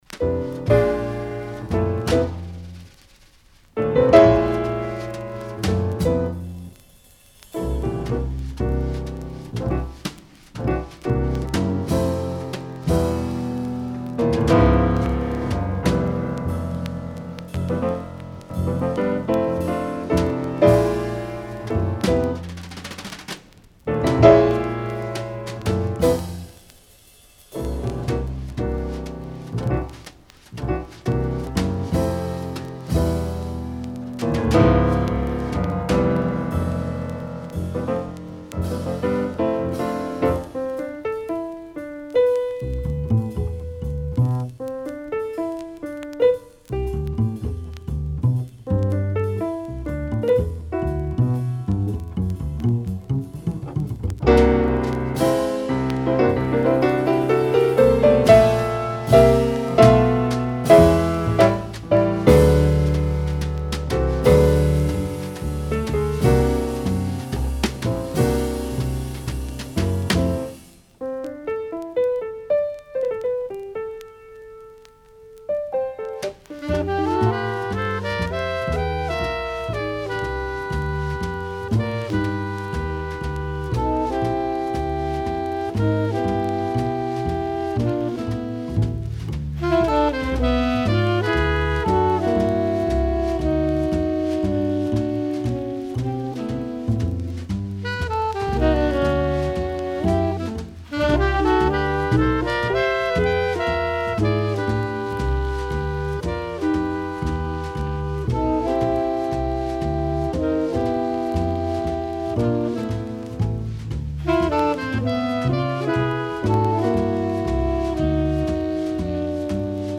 Original 1961 Japanese mono pressing
March 27, 1961 at Bunkyo Public Hall, Tokyo
piano
alto saxophone
tenor saxophone
bass
drums